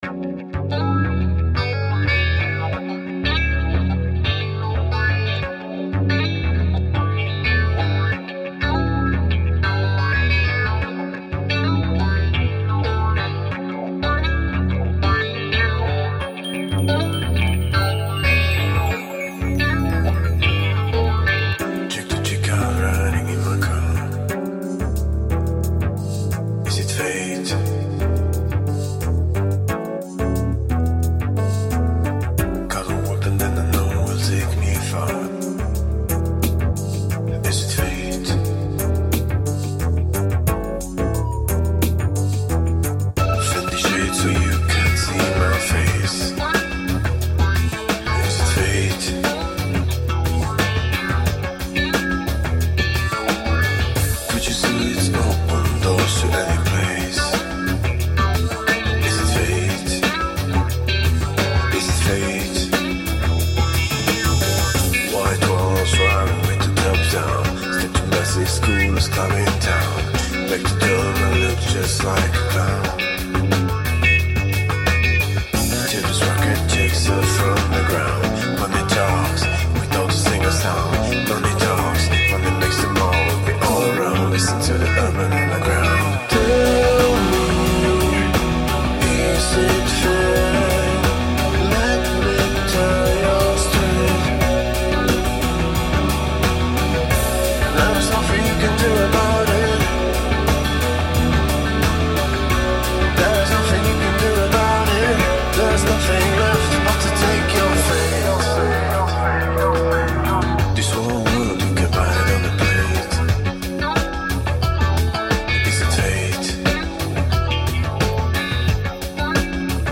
subdued and seductive